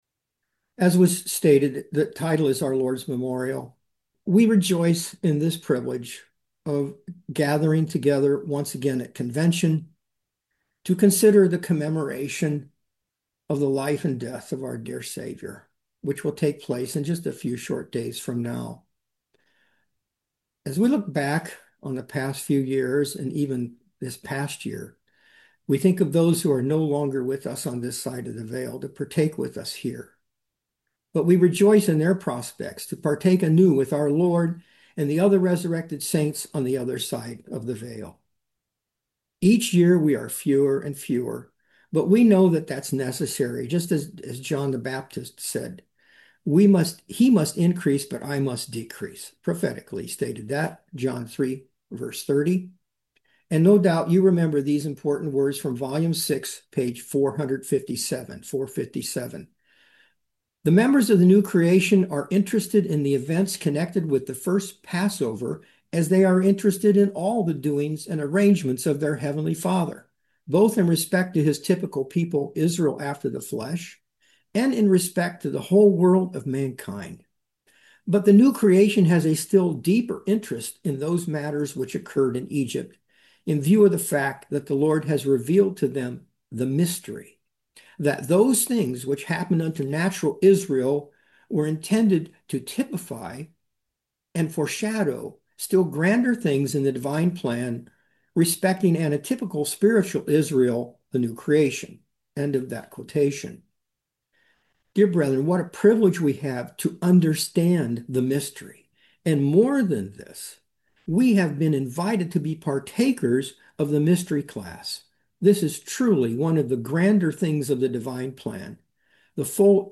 Series: 2026 Wilmington Convention
Service Type: Sermons